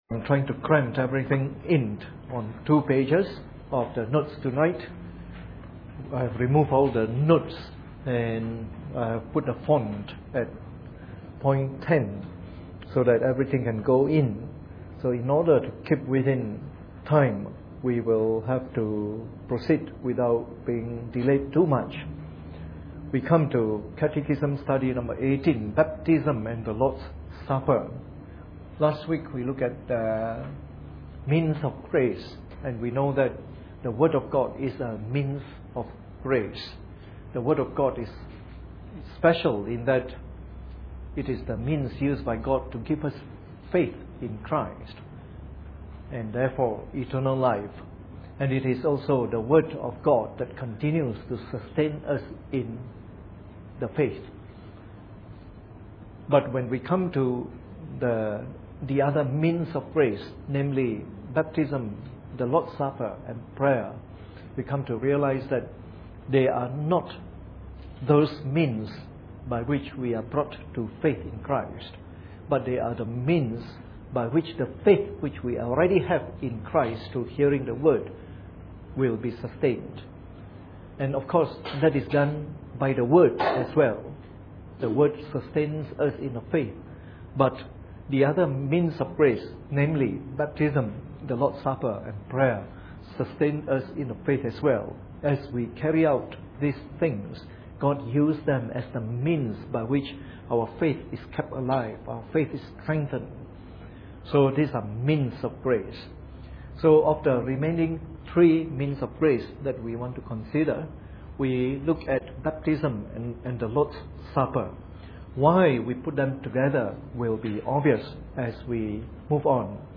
Preached on the 20th of July 2011 during the Bible Study from our current series on the Shorter Catechism.